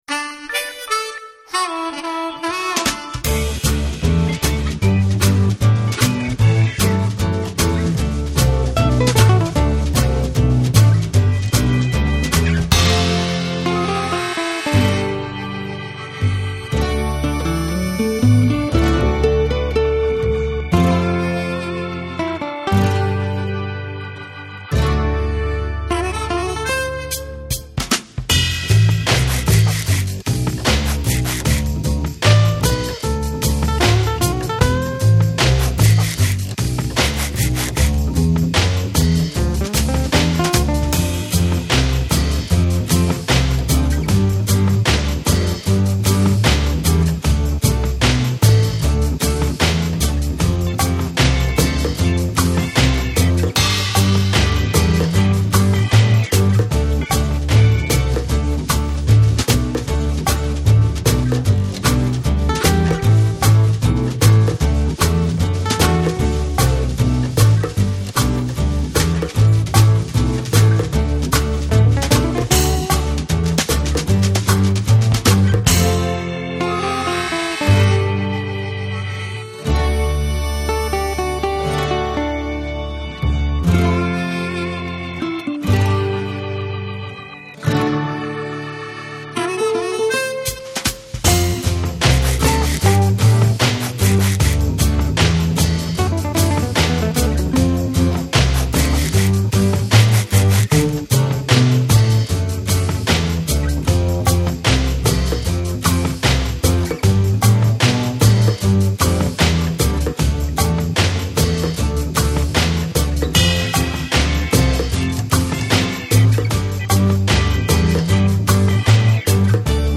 (Karaoké) MP3